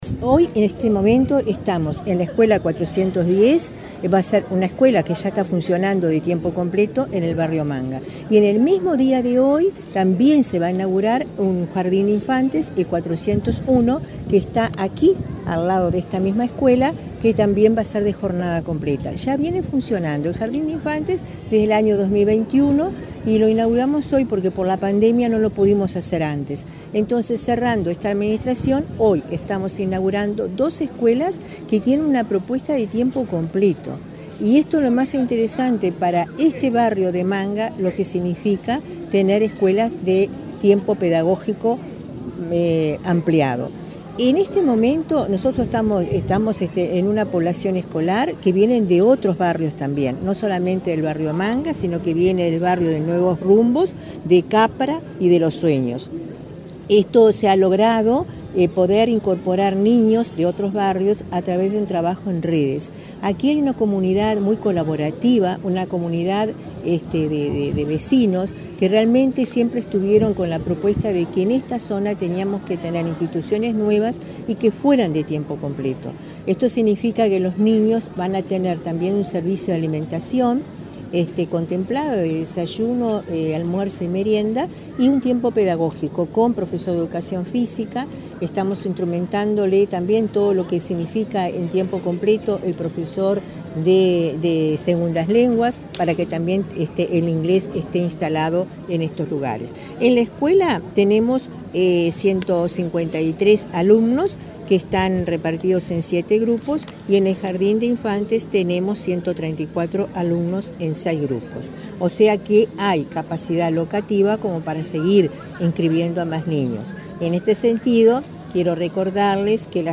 Entrevista a la directora general de Educación Inicial y Primaria, Olga de las Heras